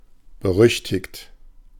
Ääntäminen
US : IPA : [ˈɪn.fə.məs]